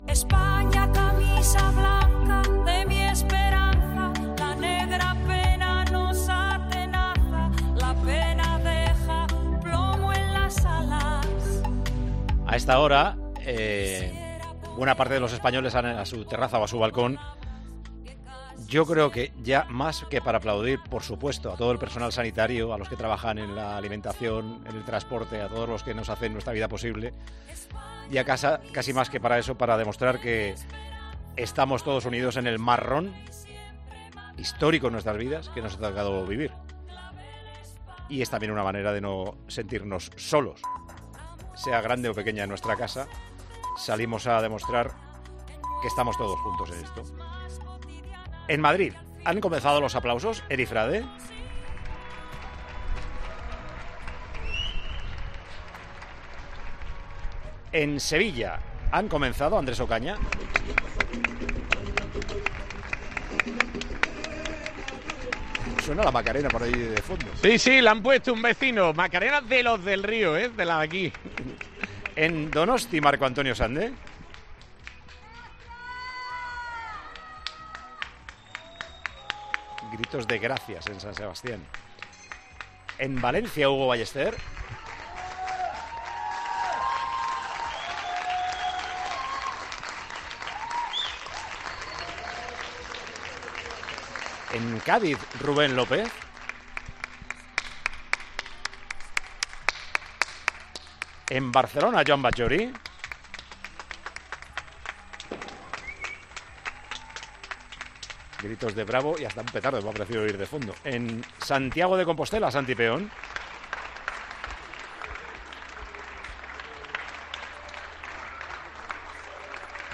De nuevo, los micrófonos de Tiempo de Juego repartidos por toda España recogieron aplausos con gestos cada vez más llamativos.